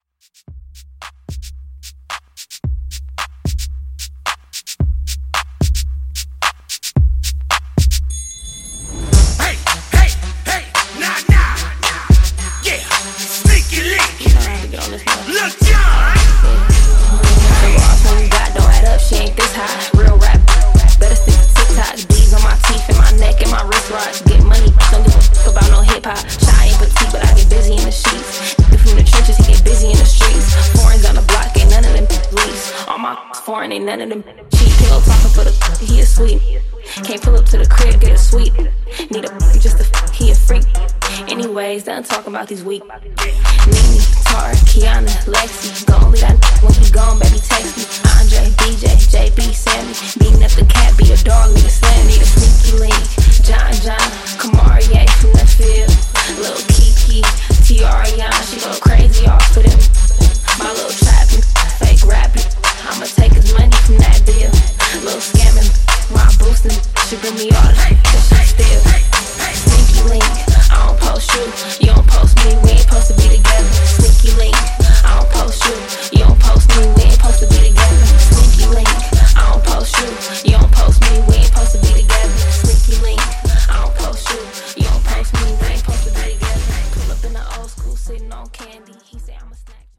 BPM: 111 Time